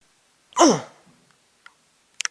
声乐 " 男性呼噜声
描述：一个人从一拳打到肚子痛苦。
标签： 人的叫声 咕噜
声道立体声